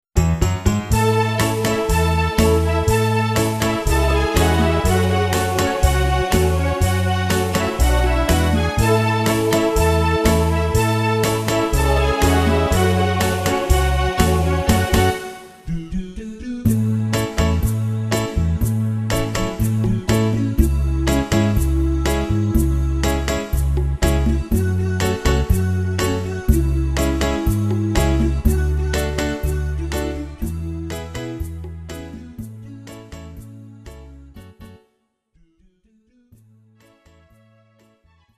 KARAOKE/FORMÁT:
MP3 ukázka